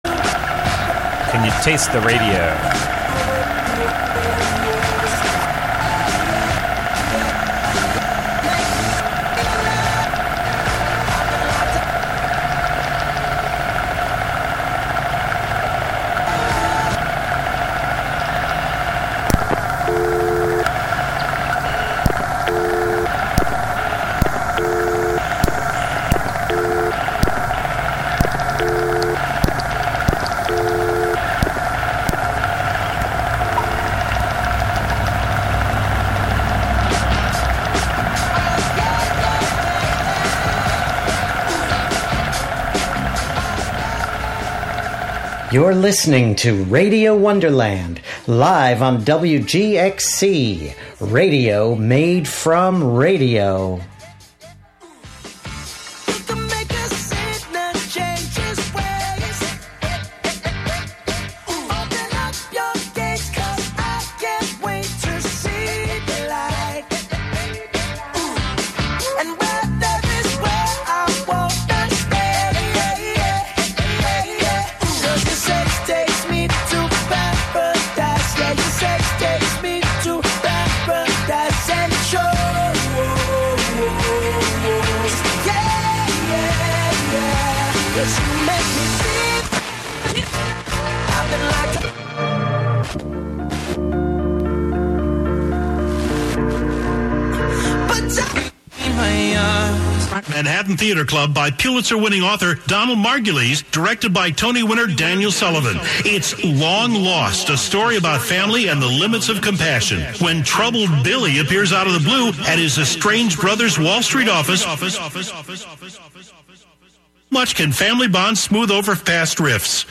11am Live from Brooklyn, New York